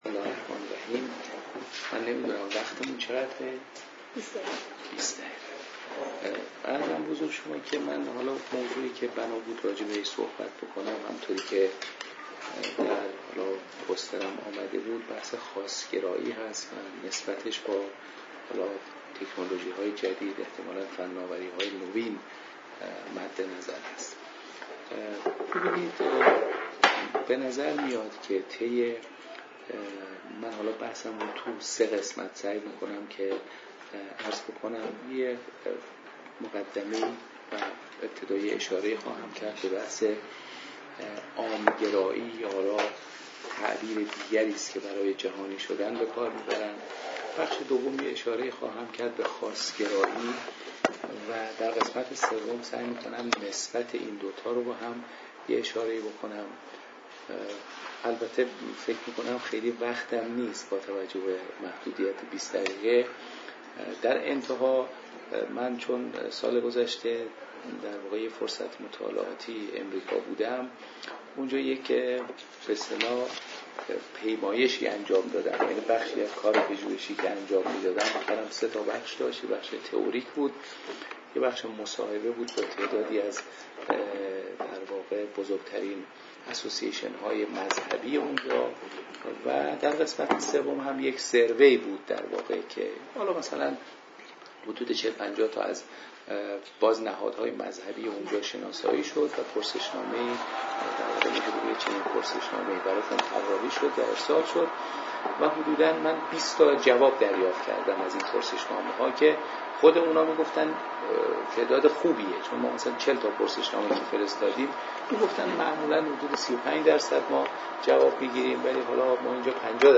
نشستی با عنوان «دین، فرهنگ و فناوری»
محل برگزاری: سالن حکمت